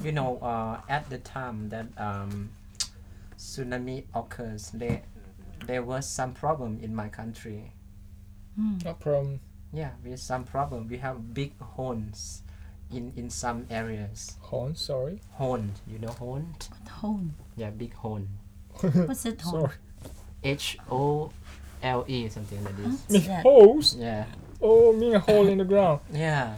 MMal : male from Malaysia, ethnically Chinese MLao : male from Laos FPhil : female from the Philippines
MLao : yeah MMal : OH you mean a HOLE in the GROUND MLao : yeah Intended Word: hole Heard as: horn, hone Discussion: MLao has [n] rather than [l] at the end of this word.